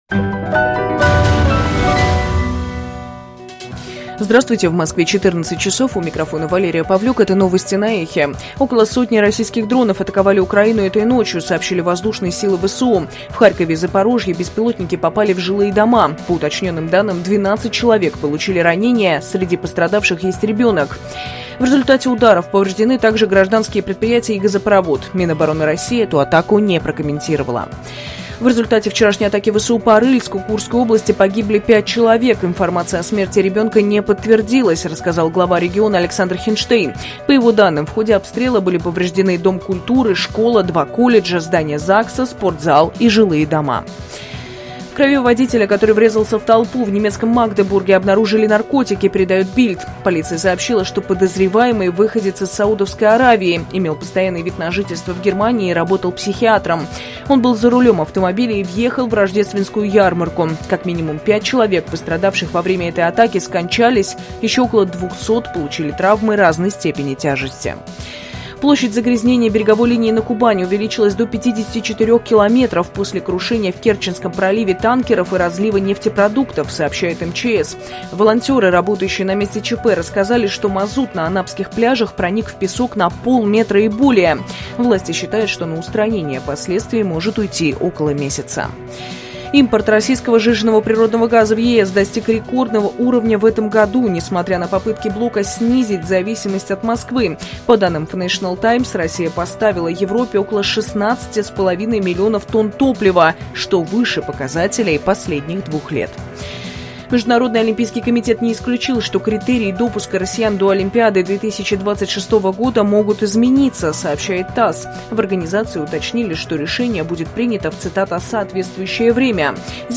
Новости 14:00